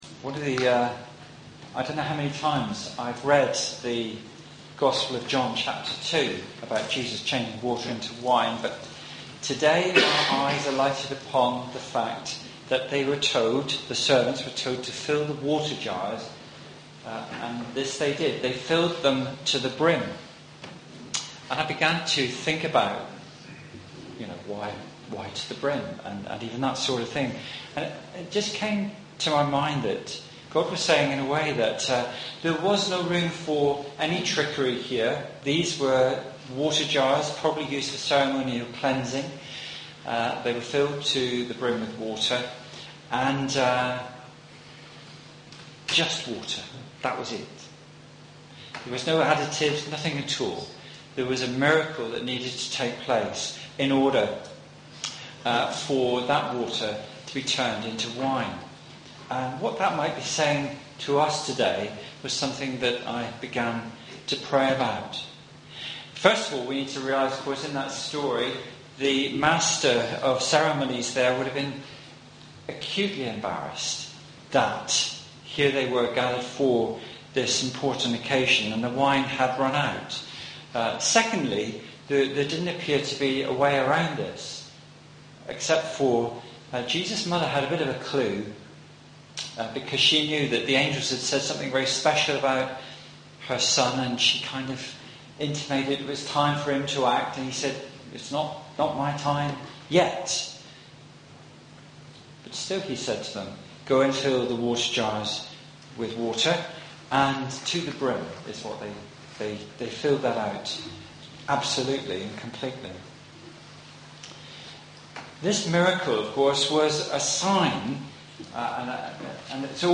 Sermon-29-Jan-17.mp3